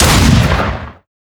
🌲 / midnight_guns mguns mgpak0.pk3dir sound weapon magnum
skin_deagle_fire_00.wav